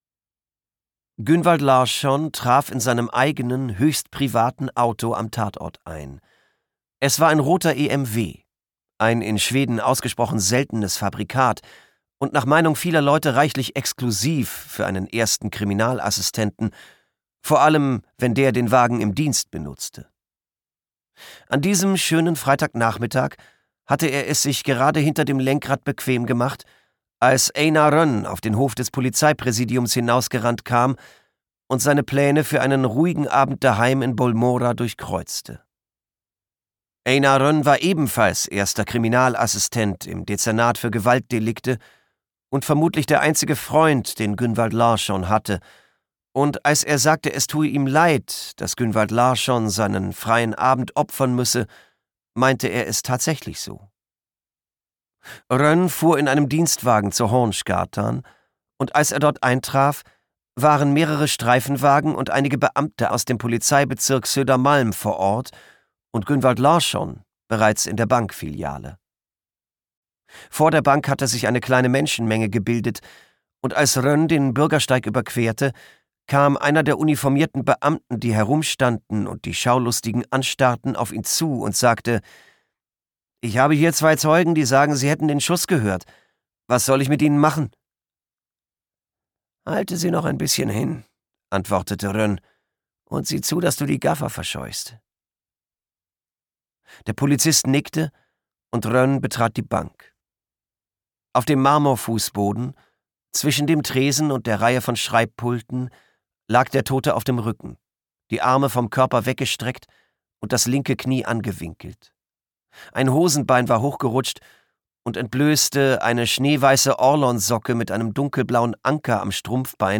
Schweden-Krimi
Gekürzt Autorisierte, d.h. von Autor:innen und / oder Verlagen freigegebene, bearbeitete Fassung.